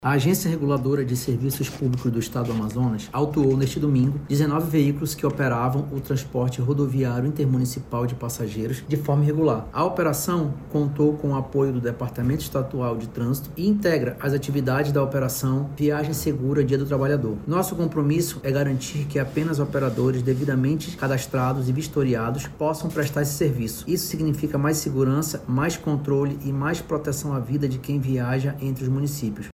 De acordo com o diretor-presidente da Arsepam, Ricardo Lasmar, a ação teve como objetivo dar mais segurança aos passageiros.